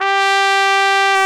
Index of /90_sSampleCDs/Roland LCDP12 Solo Brass/BRS_Cornet/BRS_Cornet 1
BRS CORNET04.wav